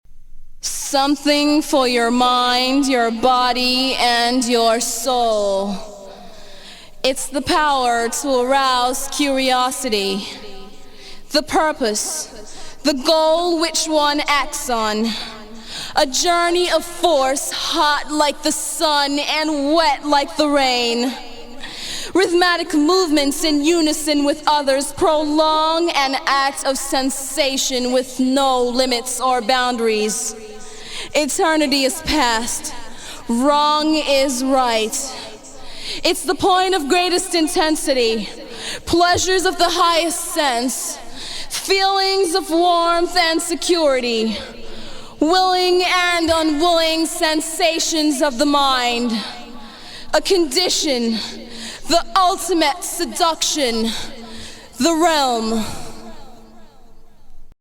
Genre: Electronic
Style: House